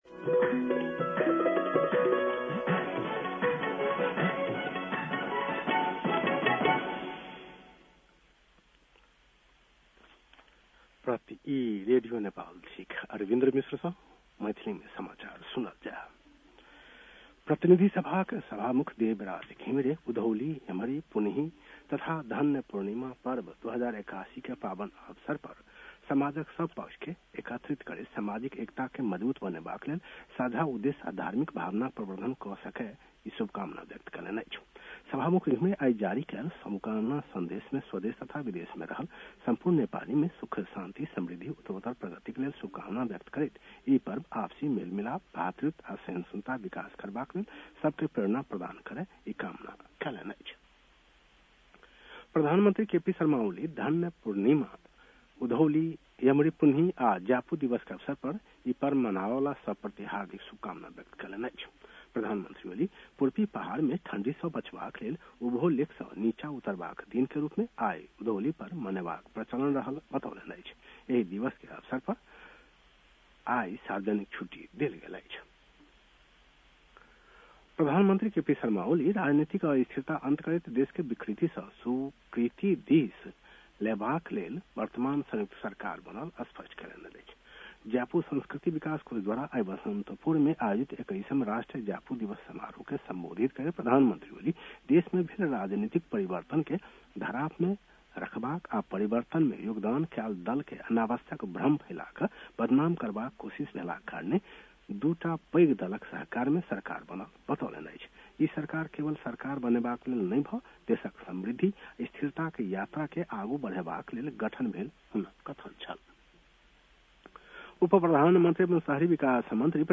मैथिली भाषामा समाचार : १ पुष , २०८१